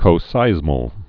(kō-sīzməl, -sīs-) also co·seis·mic (-mĭk)